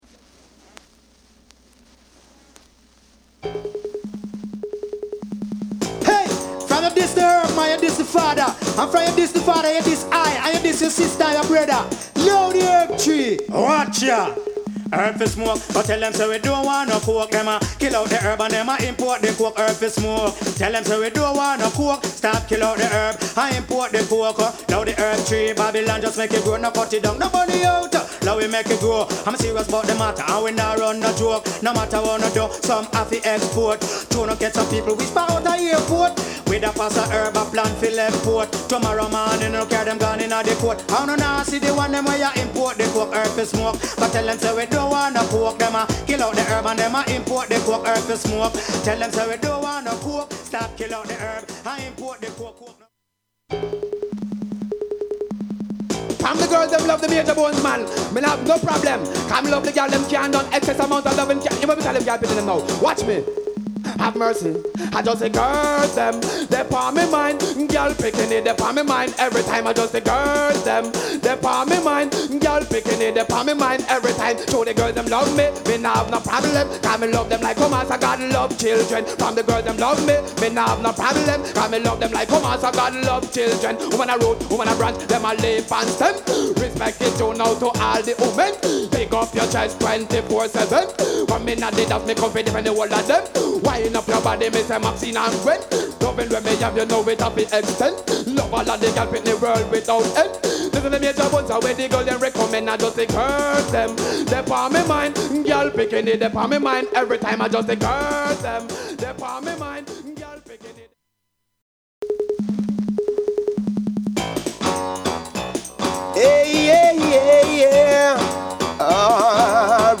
REGGAE / DANCEHALL